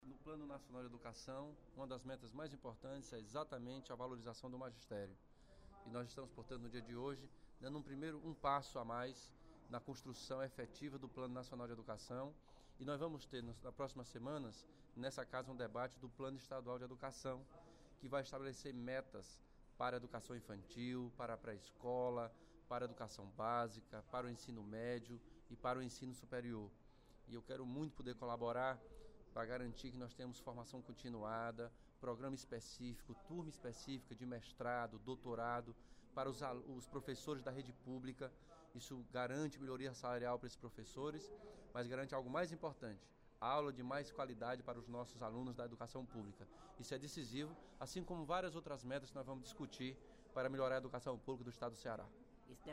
O deputado Elmano Freitas (PT) abordou, durante o primeiro expediente da sessão plenária desta sexta-feira (19/06), a importância do Plano Estadual de Educação (PEE), que definirá as metas educacionais do Ceará nos próximos 10 anos.